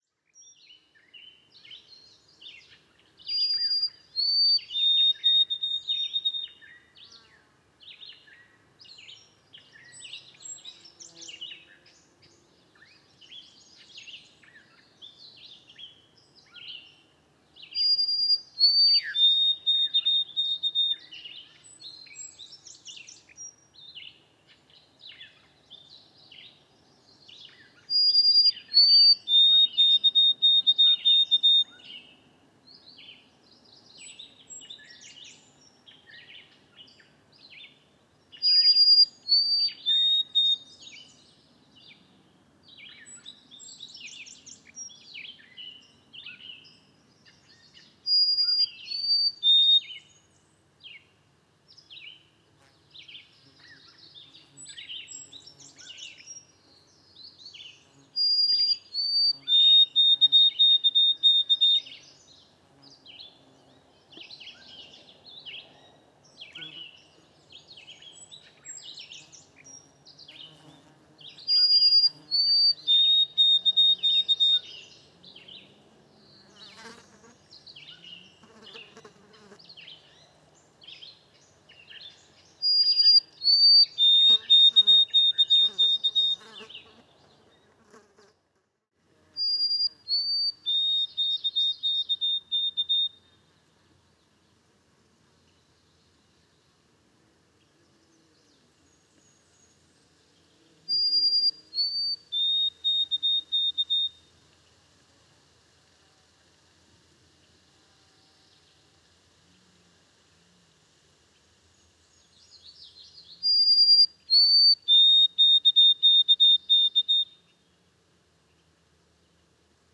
White-throated Sparrow – Zonotrichia albicollis
EASTERN TOWNSHIPS – Song
QC. June 22, 2018. 10:00AM.